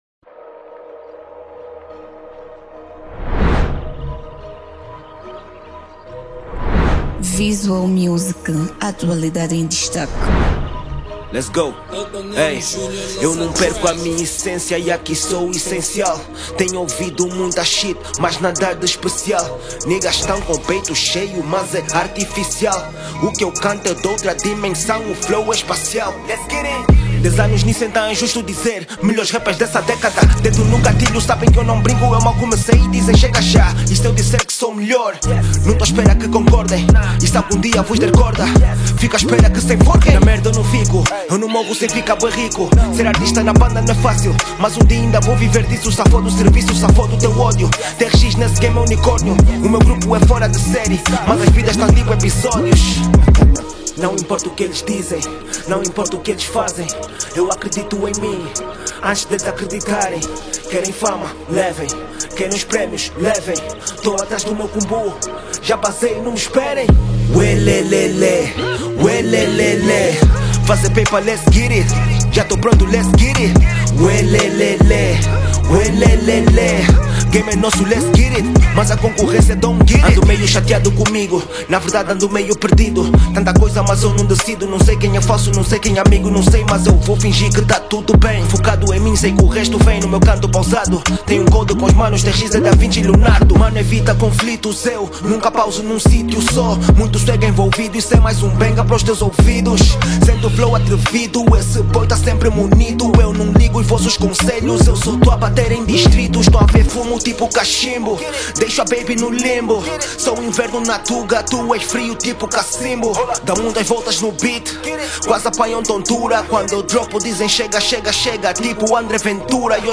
Género: RAP